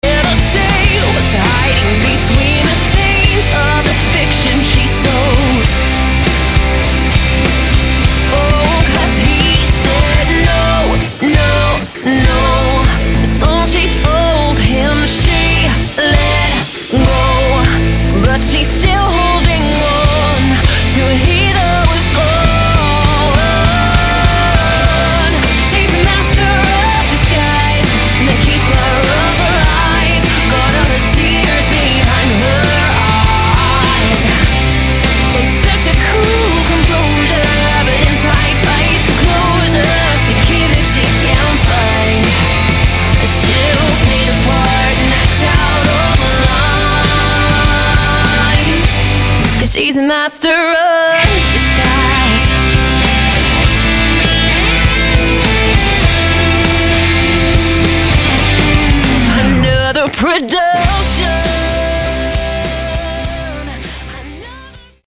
Vocals
Acoustic Guitar
Guitar/ Bass/ Drums/ Keyboard
Backing Vocals
mixing bits of rock, pop and country